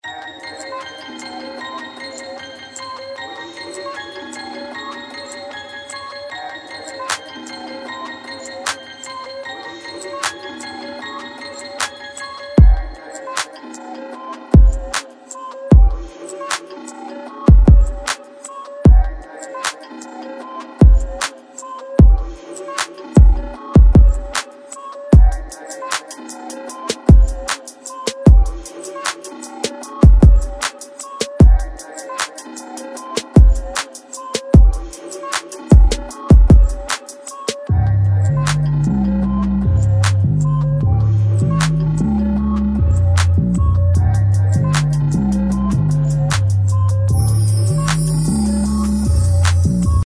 trap hop